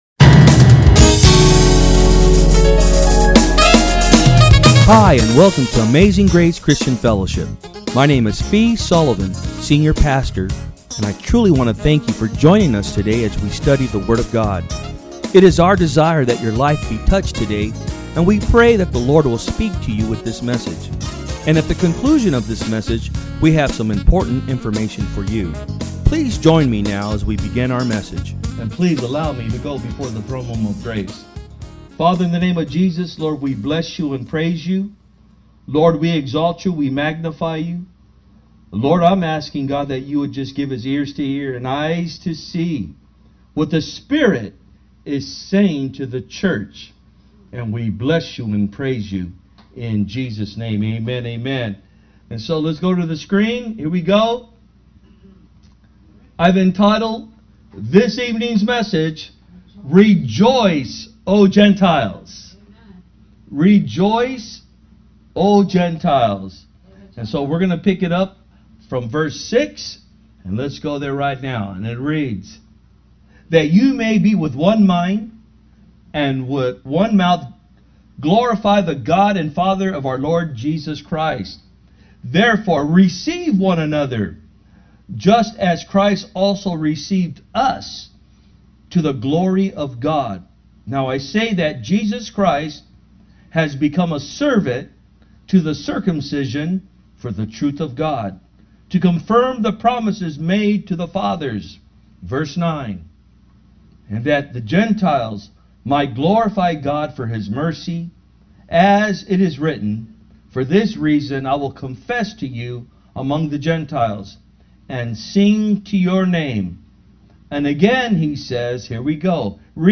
Sermons
From Service: "Wednesday Pm"